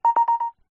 pulse_tone_android.mp3